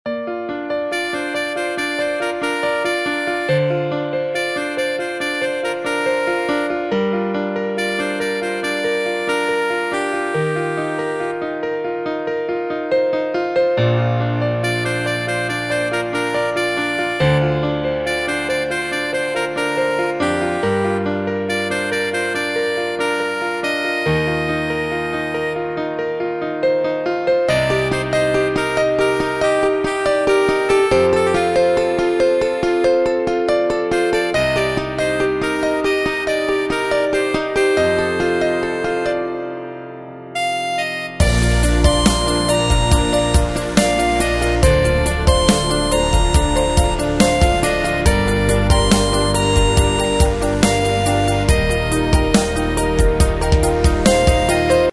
Tempo: 70 BPM.
MP3 with melody DEMO 30s (0.5 MB)zdarma